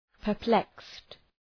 Προφορά
{pər’plekst}